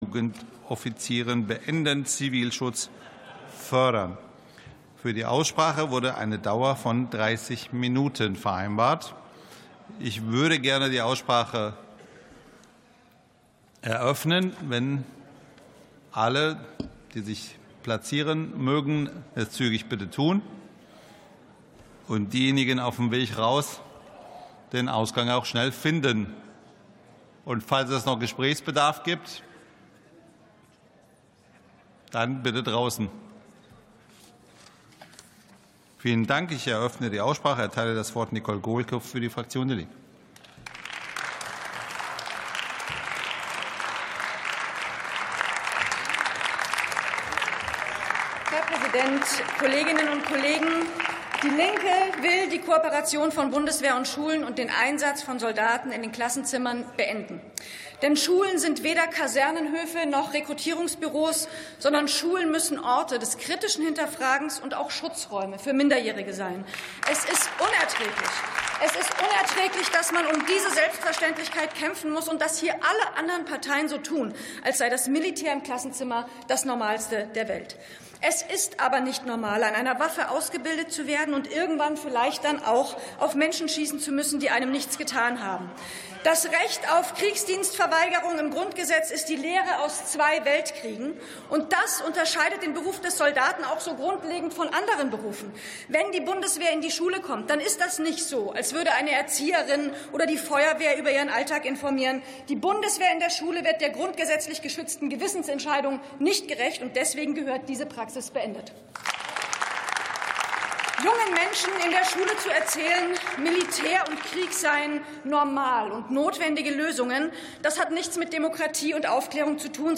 Plenarsitzungen - Audio Podcasts Deutscher Bundestag